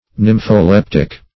Search Result for " nympholeptic" : The Collaborative International Dictionary of English v.0.48: Nympholeptic \Nym`pho*lep"tic\ (n[i^]m`f[-o]*l[e^]p"t[i^]k), a. Under the influence of nympholepsy; ecstatic; frenzied.